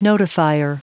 Prononciation du mot notifier en anglais (fichier audio)
Prononciation du mot : notifier